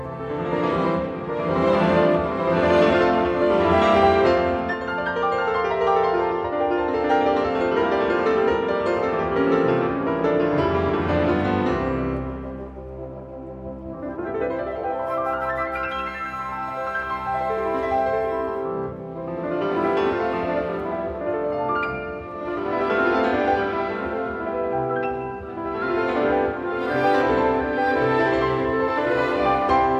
piano : concerto
Musique Classique